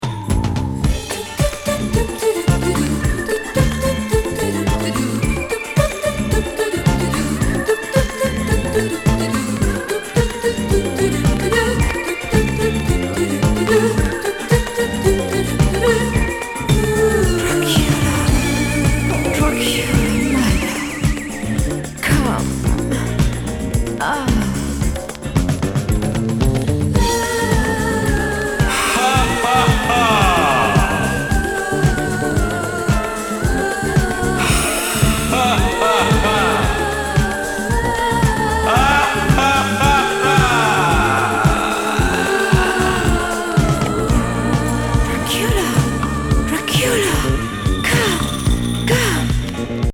イロモノ?ディスコ4曲入りコンピ。